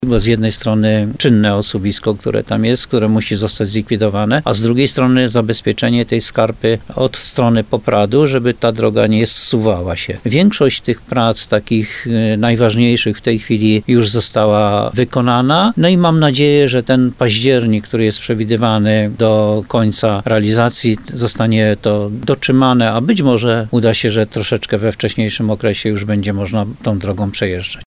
Zabezpieczenie osuwiska i przebudowa odcinka drogi między Muszyną i Leluchowem ma się zakończyć w październiku – informuje burmistrz Jan Golba.